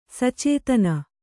♪ sacētana